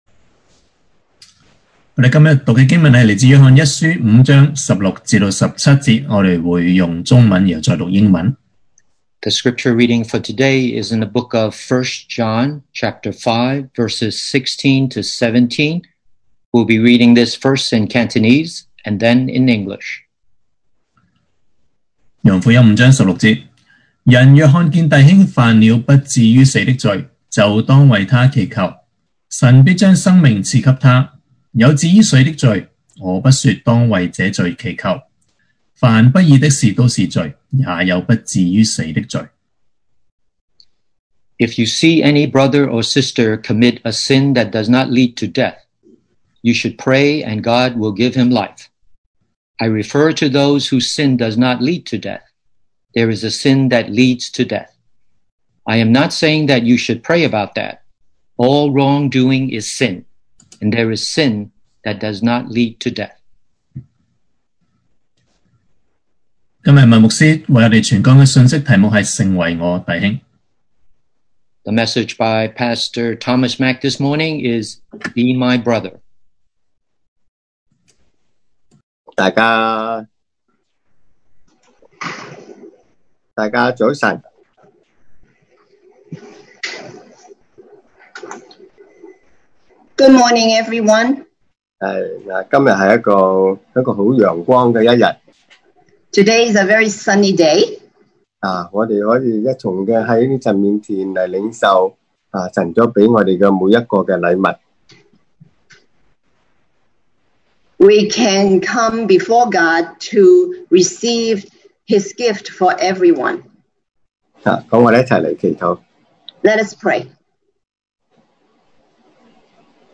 2020 sermon audios 2020年講道重溫 Passage: 1 John 5:16-17 Service Type: Sunday Morning Be My Brother!